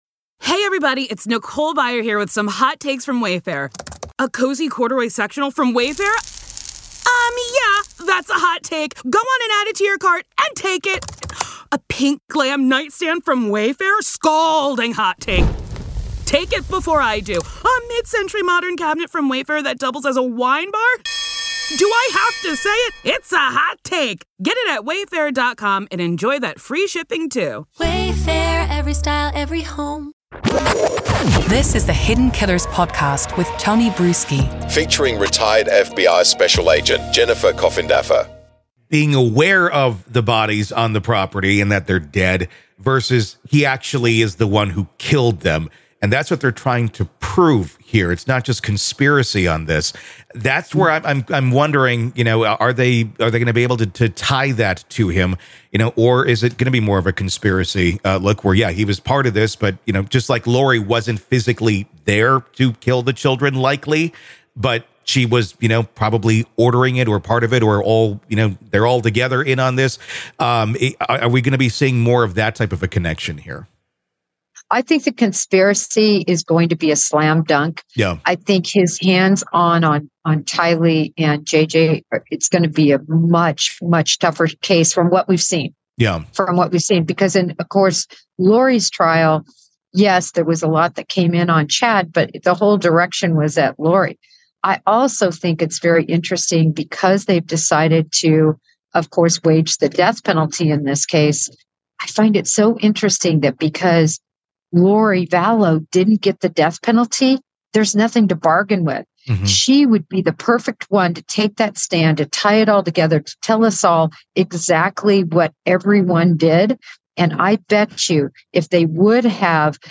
The conversation delves into the strategic decisions made by the defense and the implications of these decisions on the future of the case. **Main Points of the Conversation:** - Discussion on the likelihood of conspiracy charges being more successful against Chad Daybell due to the lack of direct evidence linking him to the murders.